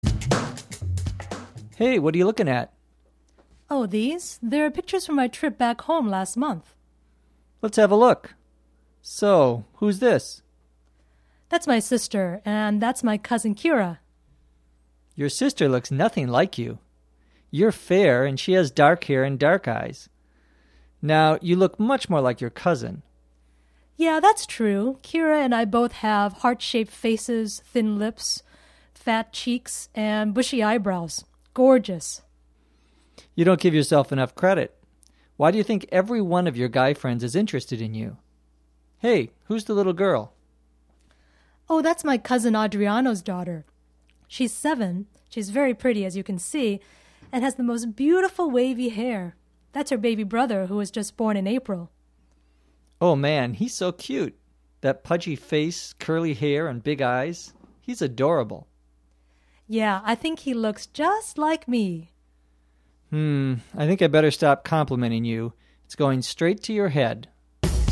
03-Describing people's looks conversation